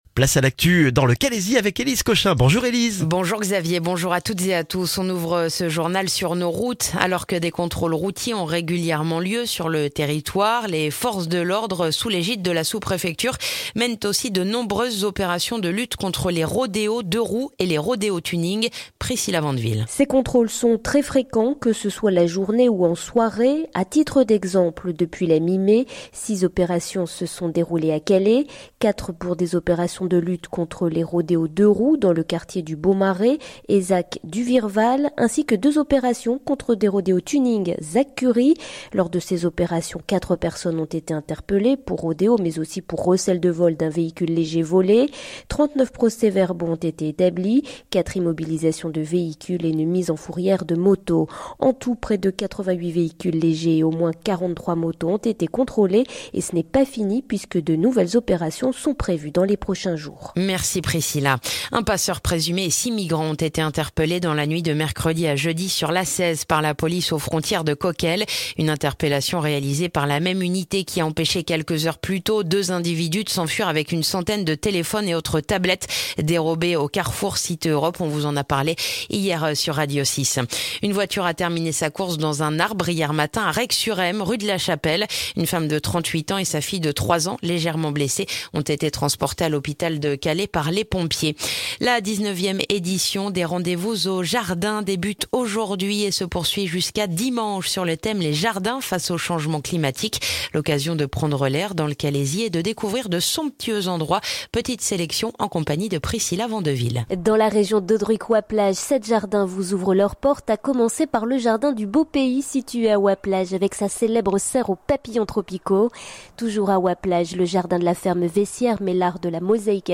Le journal du vendredi 3 juin dans le calaisis